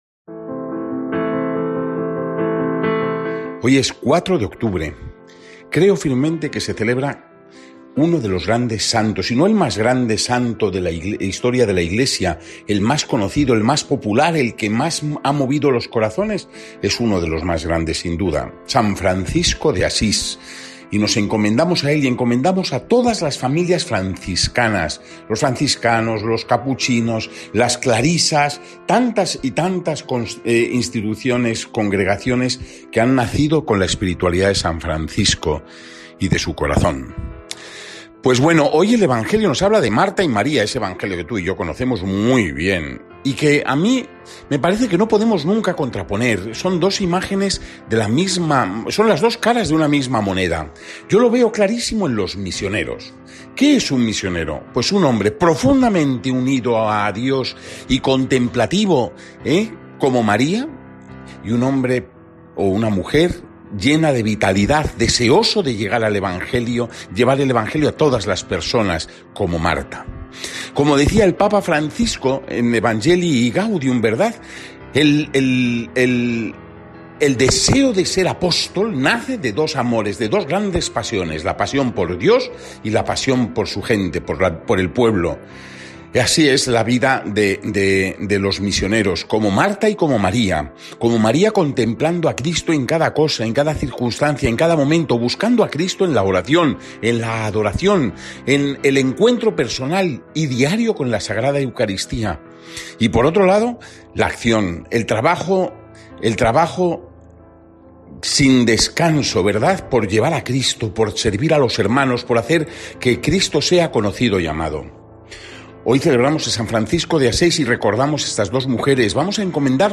Meditación